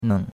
neng3.mp3